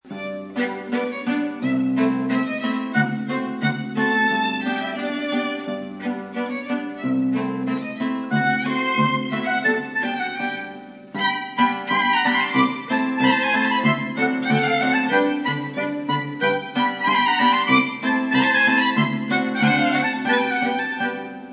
Aufgenommen am 1. - 2. Mai 2000 in Wien